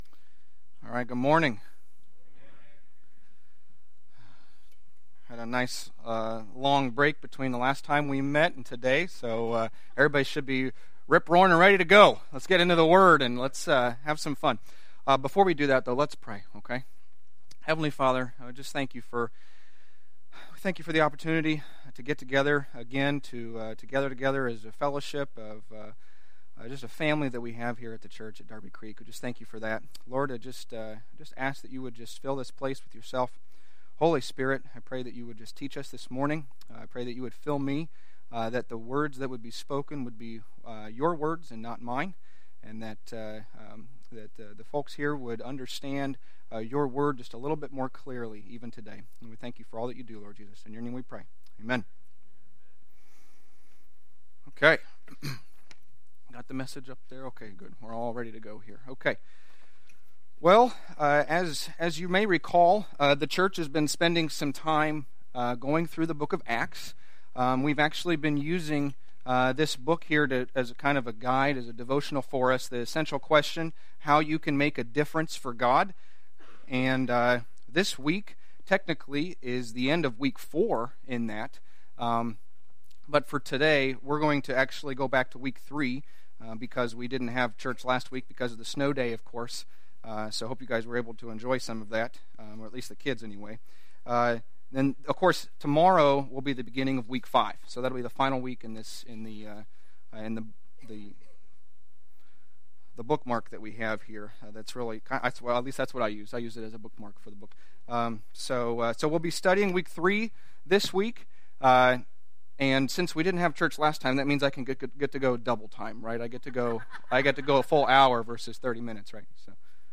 A message from the series "Stand Alone Sermons."
But make no mistake, this is not just an interview, there are applications for your own life.